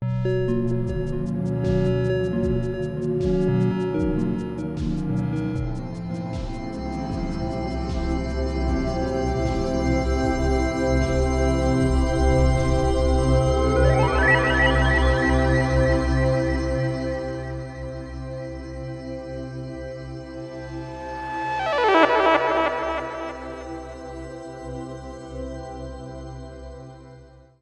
The second track is more symphonic, featuring ambient synth with a light futuristic beat. Both tracks include space-age, engine like sounds, representing Eversley’s work in aerospace engineering, and LA in the sixties.